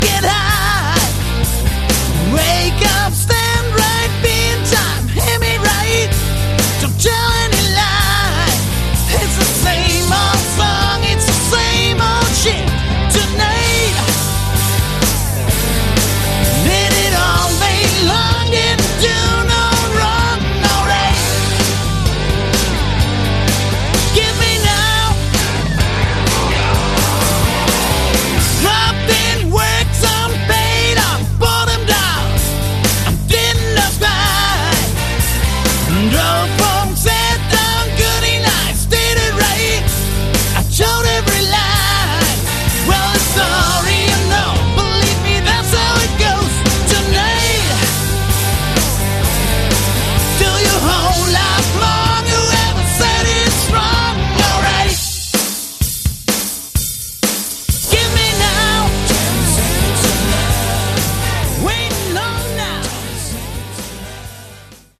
Category: Hard Rock
Straight ahead hard rock, a few cool riffs and hooks.